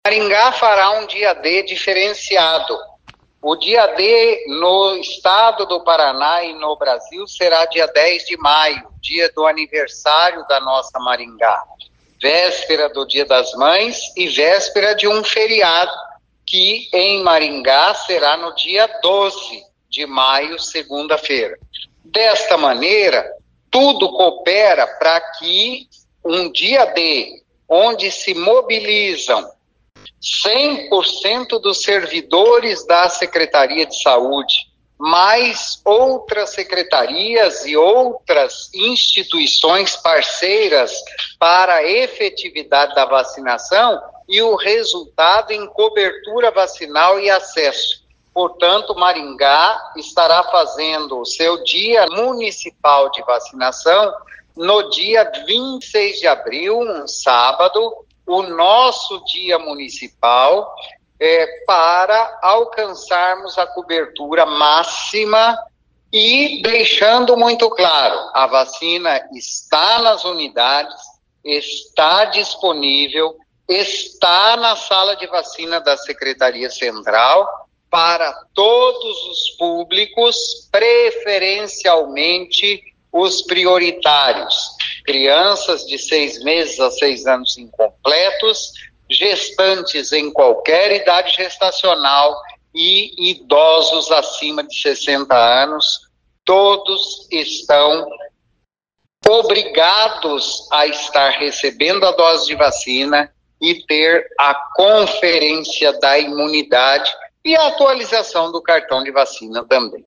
No próximo dia 26 de abril a Secretaria de Saúde de Maringá irá promover o Dia D de vacinação contra a gripe com a abertura de todas as unidades de saúde. O secretário Antônio Carlos Nardi explica que o Dia D na cidade será realizado antes do Dia D nacional por causa do aniversário de Maringá.